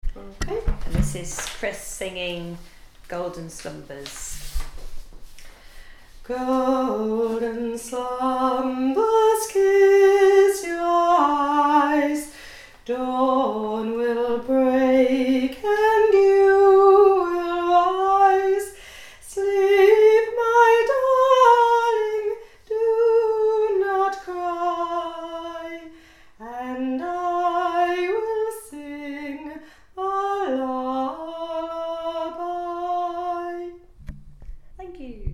Lullaby recording 公开